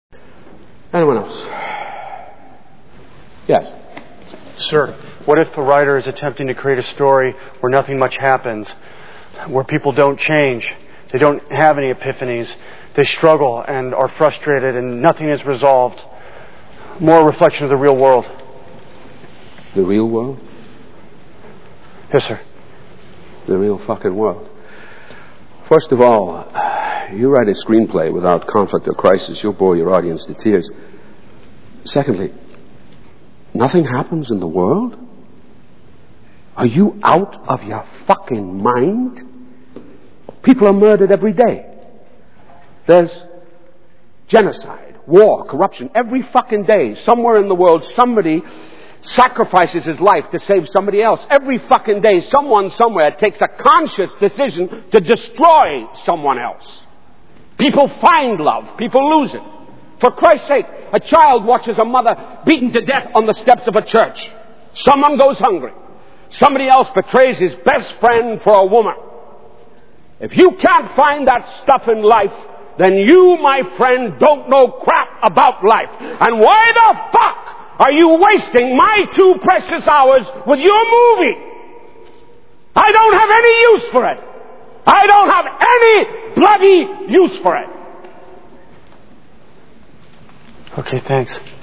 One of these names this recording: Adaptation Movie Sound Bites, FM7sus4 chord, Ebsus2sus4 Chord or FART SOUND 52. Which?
Adaptation Movie Sound Bites